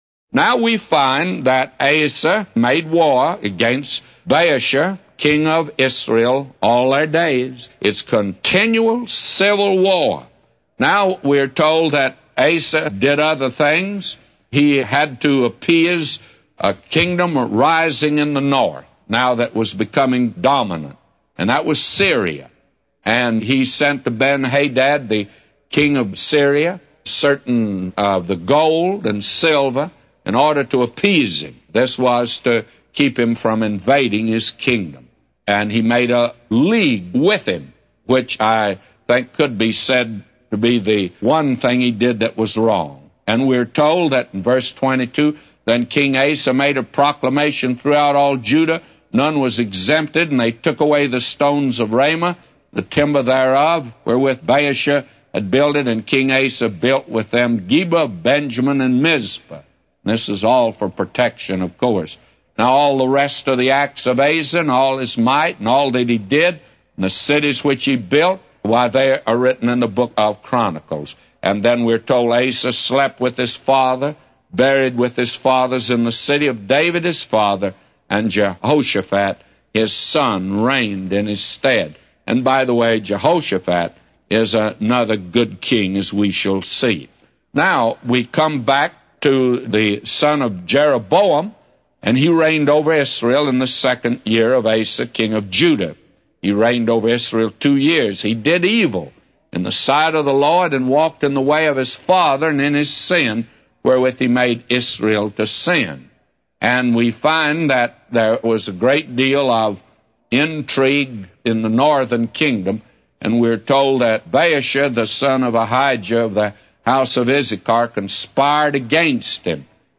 A Commentary By J Vernon MCgee For 1 Kings 15:16-999